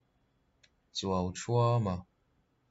Captions English Native pronunciation of the Mizo name Chuauthuama